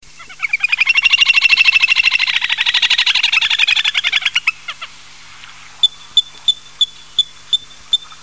Grèbe castagneux
Tachybtus ruficollis